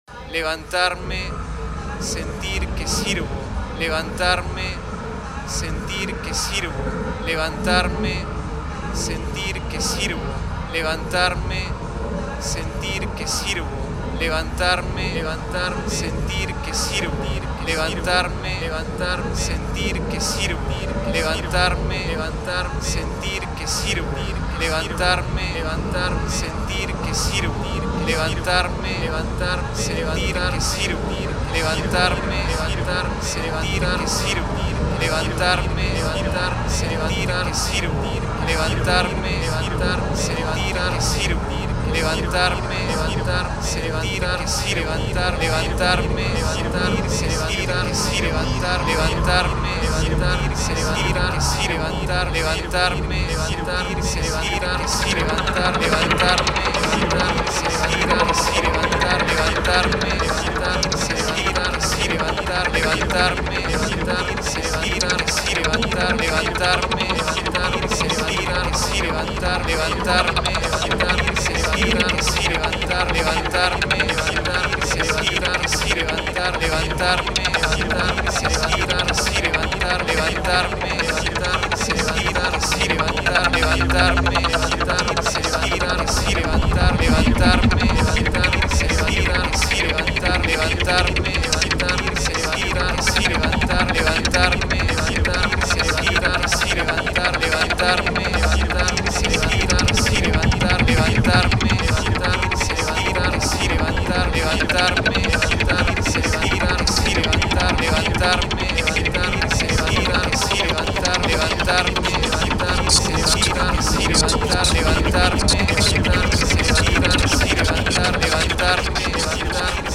Toronto-based electronic musicians